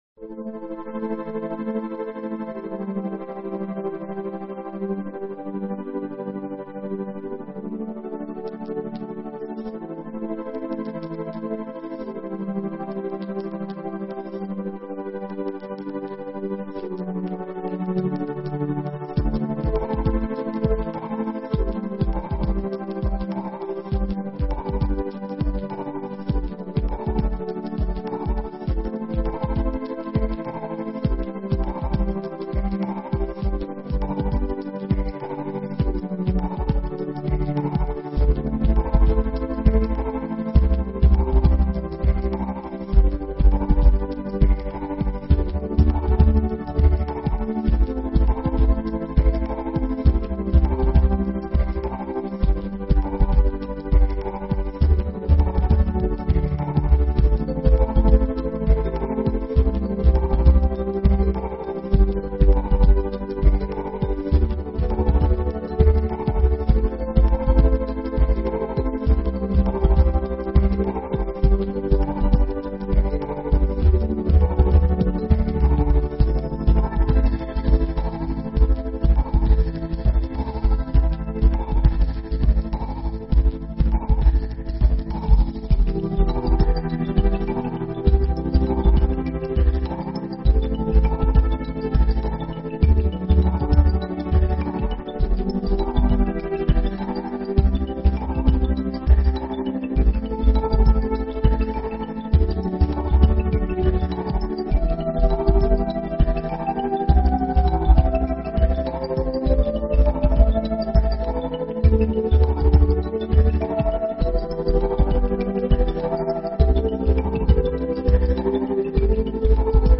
in Tremolo Effect